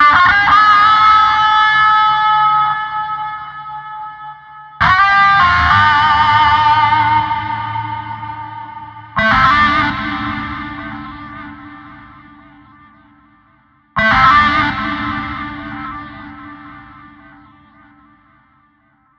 描述：摇摆的蓝调摇滚
Tag: 吉他 贝司 风琴 铃铛 铜管 摇摆 爵士 圣诞 节日 霙的秘密 米斯特 蓝调 摇滚